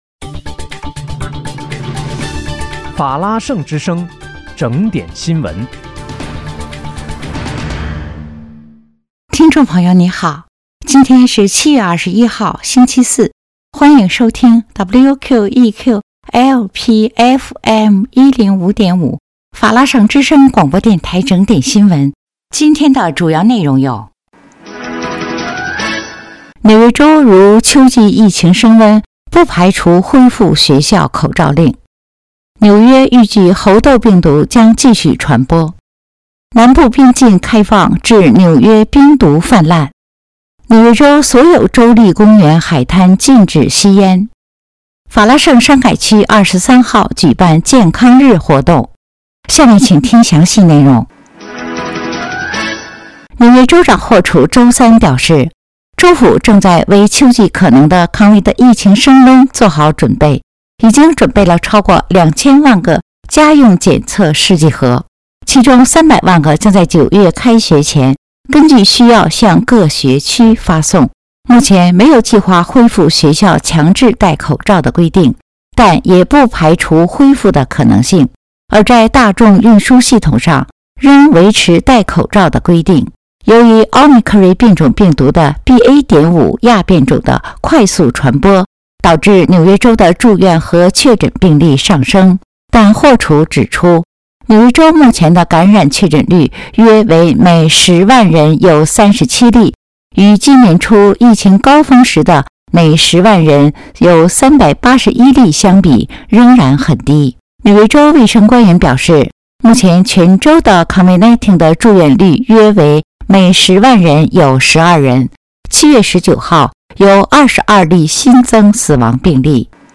7月21日（星期四）纽约整点新闻